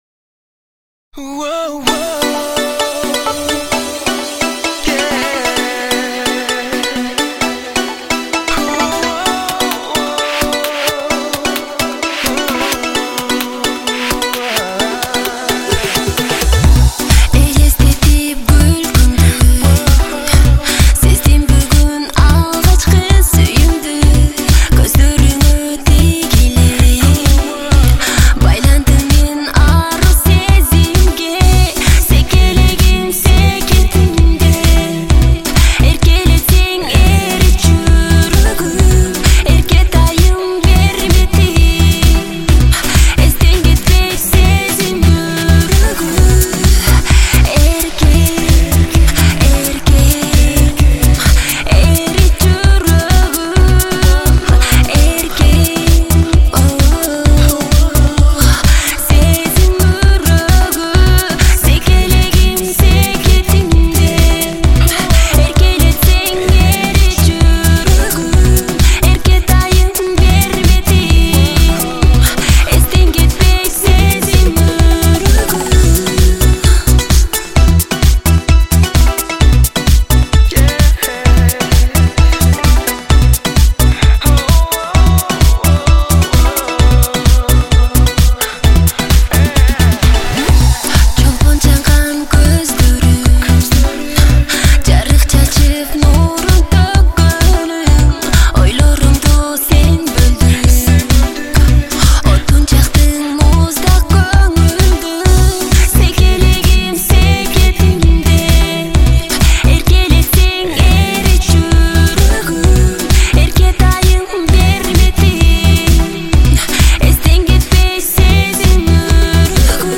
КЫРГЫЗЧА ЖАГЫМДУУ ЖАҢЫ ЫРЛАР